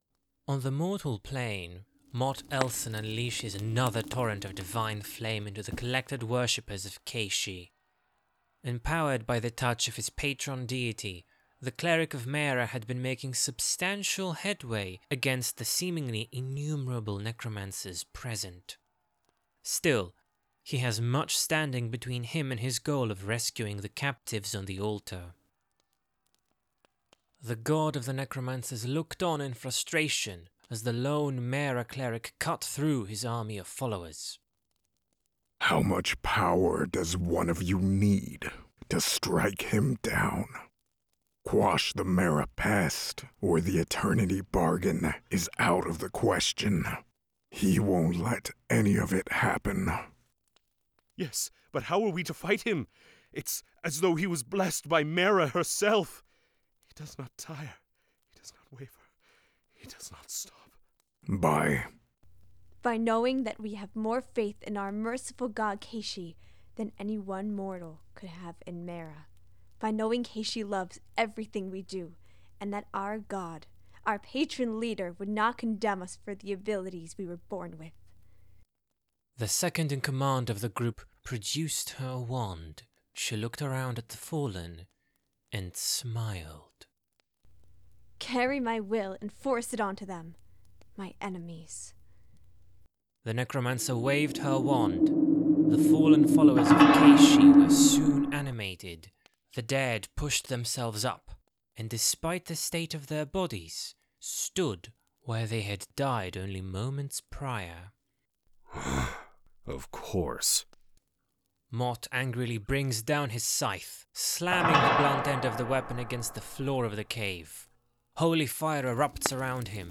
Play Rate Apps Listened List Bookmark Share Get this podcast via API From The Podcast Anthologies of Ullord 1 This is a full-cast, fantasy genre audio fiction series from Phantasmal Bard Studios.